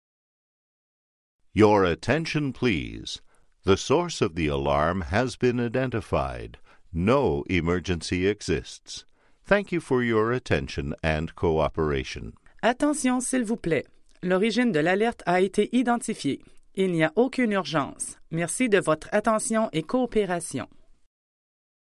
H&S – AIRPORT ALARMS & EVACUATION ALERT
STAGE 1 – SOURCE OF ALARM IDENTIFIED
STAGE-1-SOURCE-OF-ALARM-IDENTIFIED.mp3